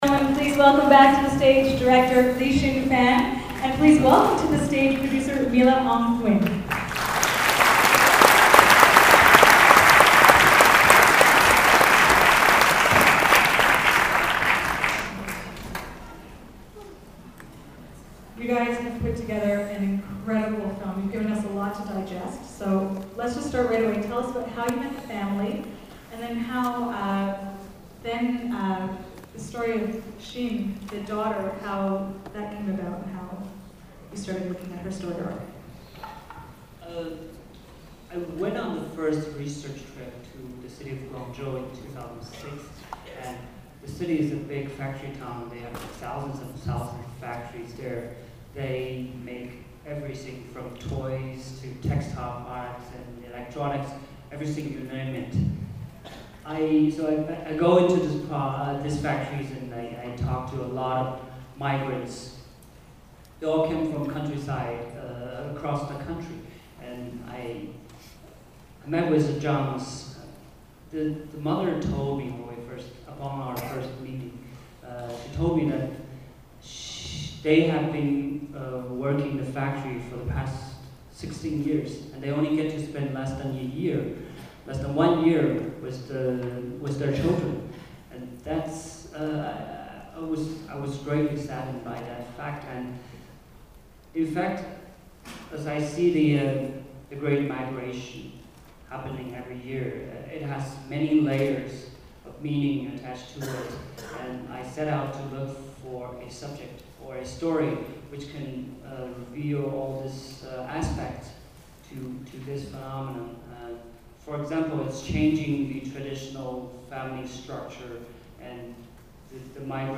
lasttrainhome_qa.mp3